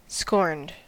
Ääntäminen
Ääntäminen US Haettu sana löytyi näillä lähdekielillä: englanti Käännös 1. indignātus Scorned on sanan scorn partisiipin perfekti.